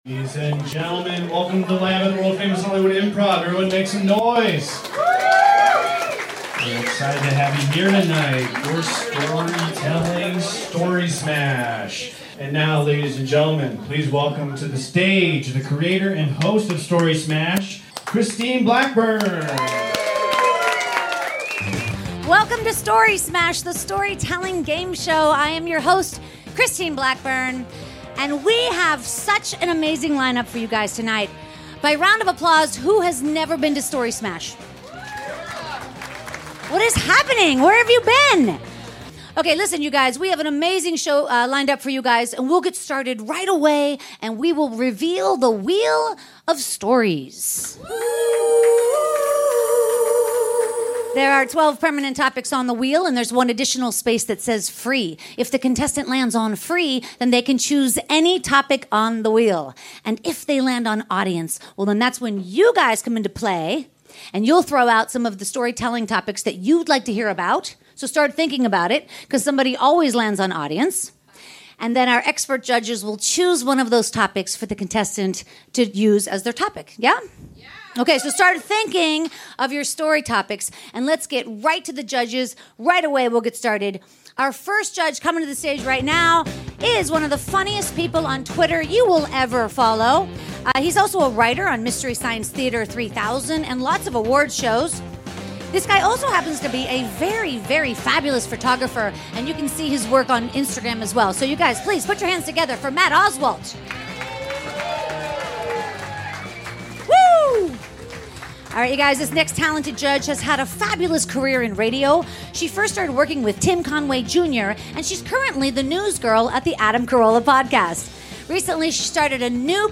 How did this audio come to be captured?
Recorded April 27th at The Hollywood Improv.